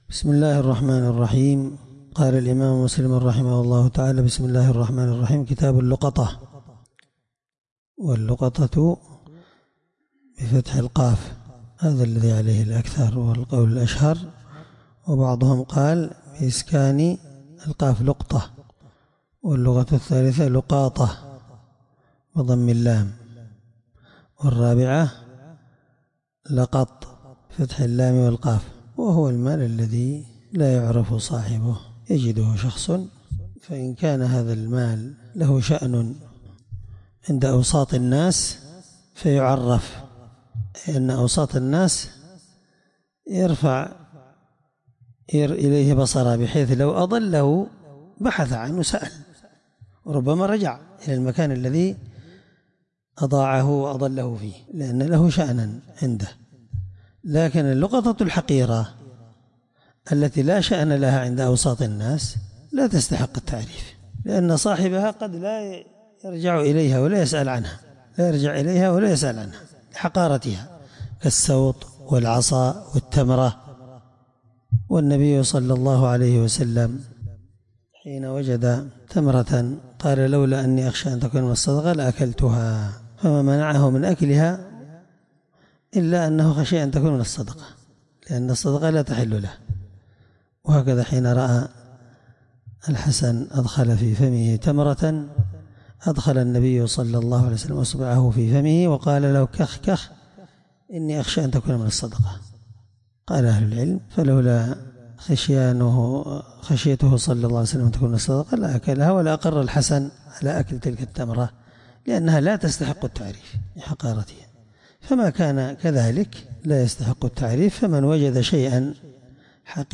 الدرس1من شرح كتاب اللقطة الحدود حديث رقم(1722) من صحيح مسلم